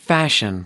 /ˈleʒ.ər/